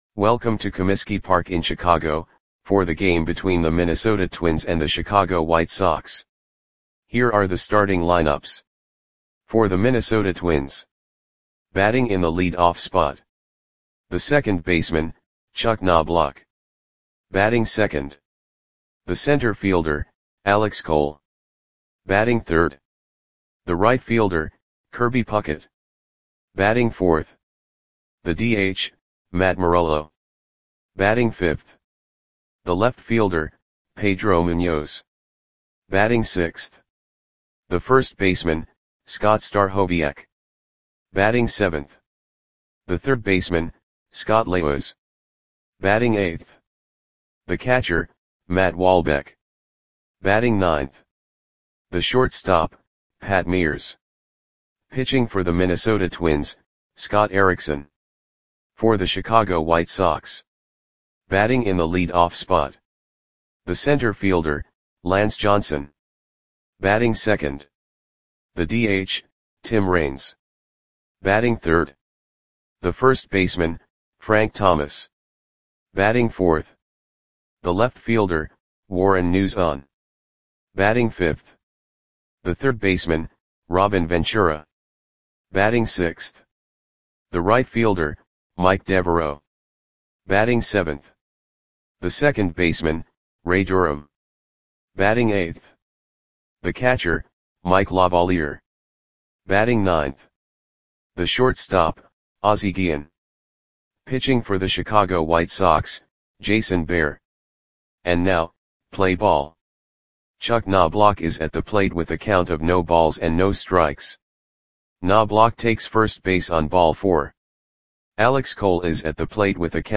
Audio Play-by-Play for Chicago White Sox on May 10, 1995
Click the button below to listen to the audio play-by-play.